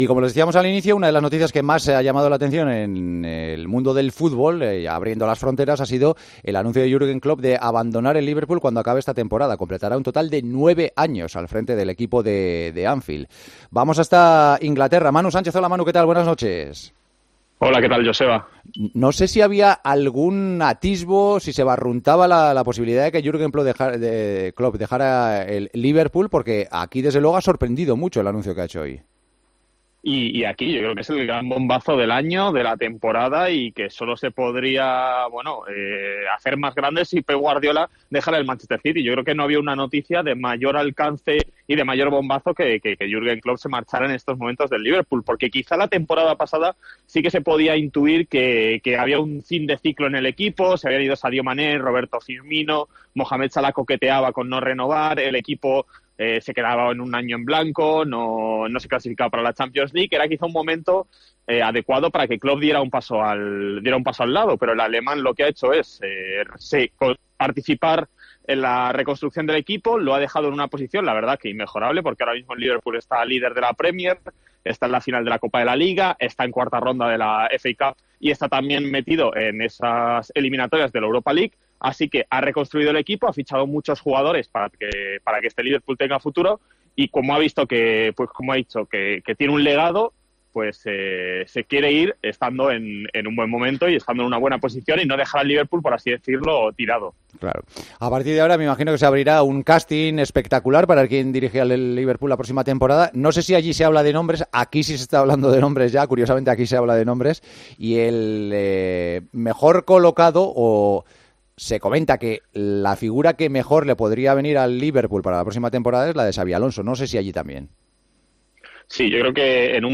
Reacciones a la despedida desde Inglaterra